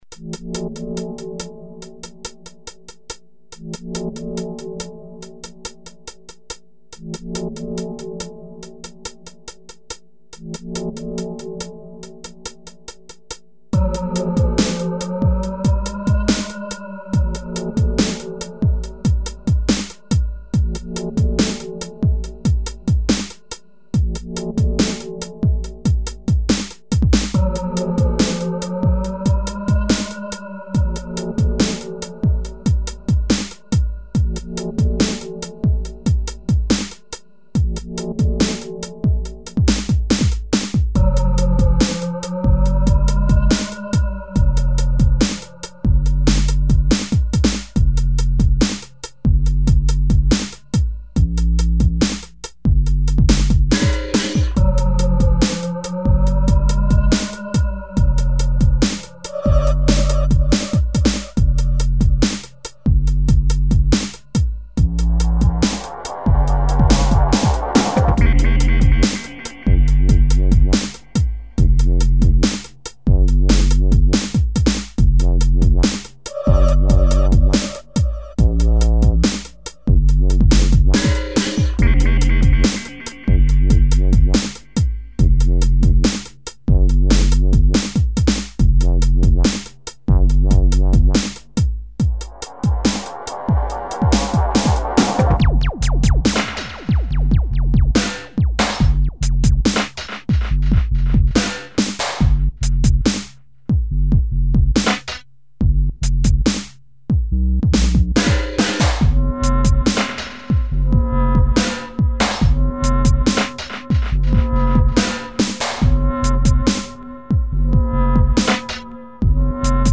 Here's an '04 grime mix from the man
Absolutely chocked full of obscuro white labels, I'm sure.